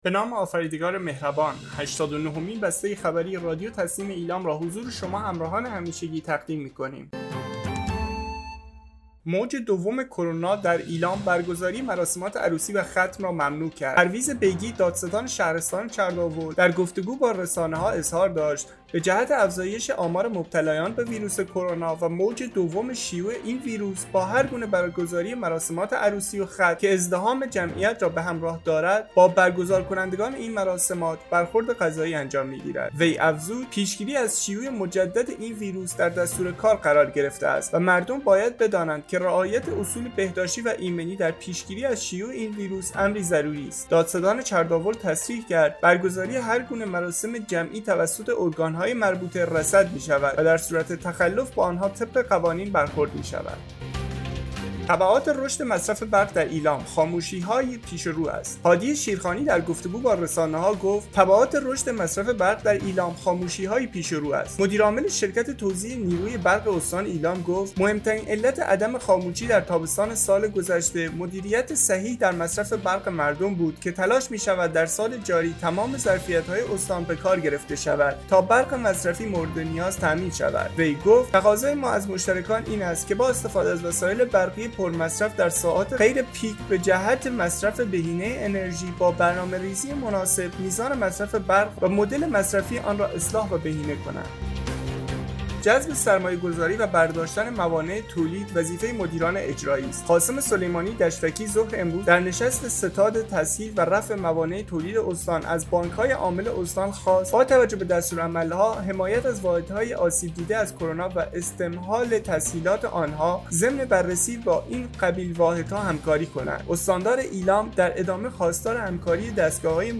به گزارش خبرگزاری تسنیم از ایلام, هشتاد و نهمین بسته خبری رادیو تسنیم استان ایلام با خبرهایی چون؛ موج دوم کرونا در ایلام برگزاری‌ مراسمات عروسی و ختم را ممنوع کرد، تبعات رشد مصرف برق در ایلام، خاموشی‌های پیش رو است و جذب سرمایه‌گذاری و برداشتن موانع تولید وظیفه مدیران اجرایی است منتشر شد.